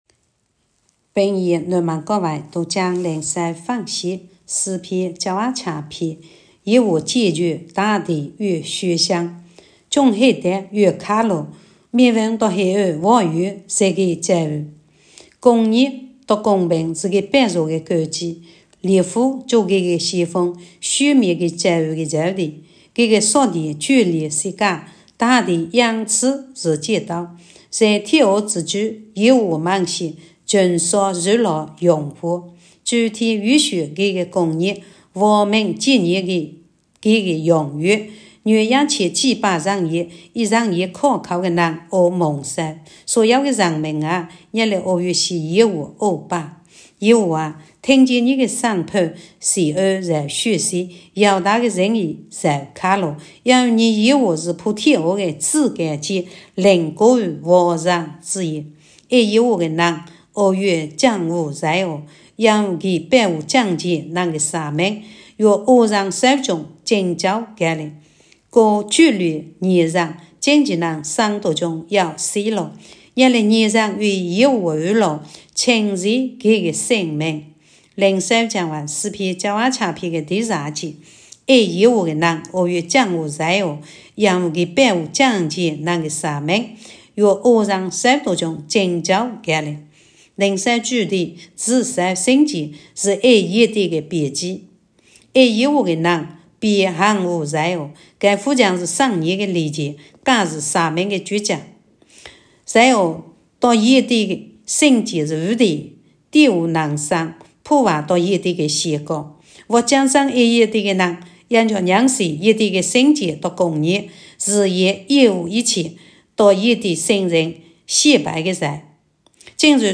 平阳话朗读——诗97